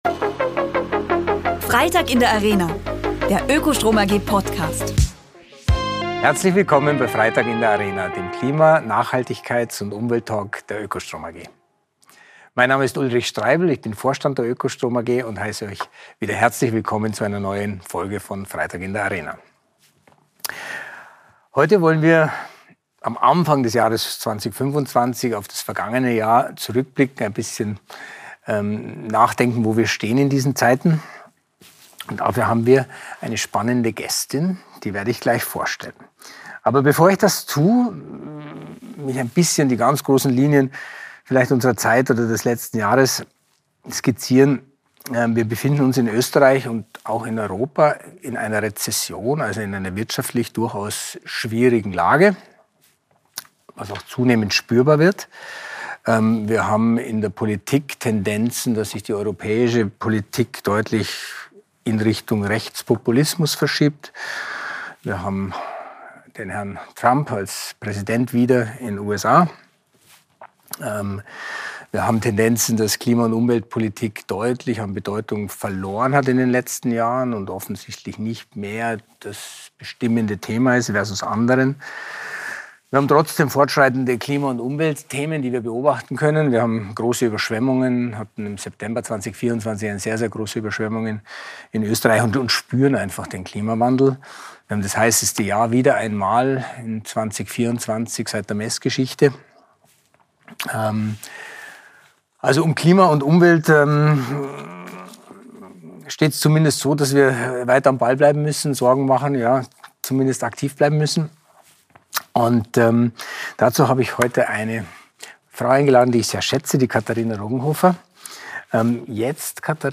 Klima- und Nachhaltigkeits-Talk der oekostrom AG